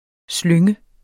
Udtale [ ˈsløŋə ]